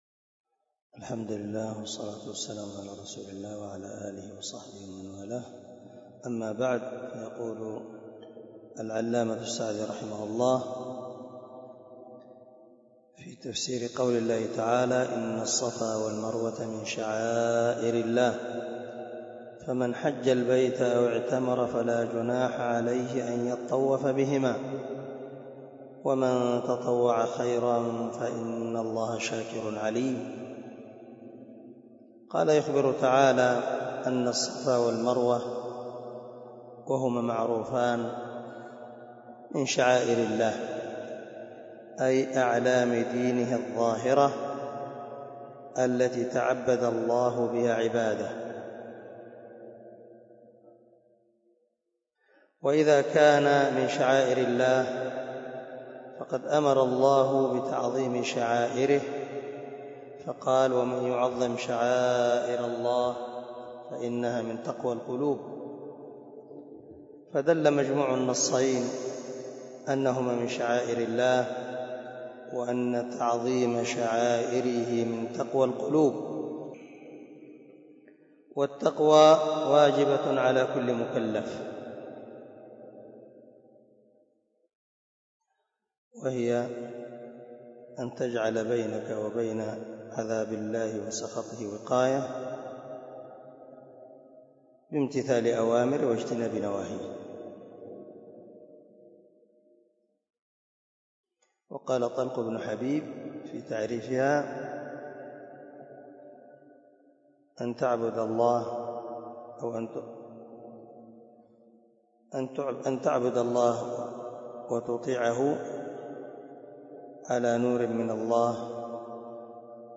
068الدرس 58 تابع تفسير آية ( 158 ) من سورة البقرة من تفسير القران الكريم مع قراءة لتفسير السعدي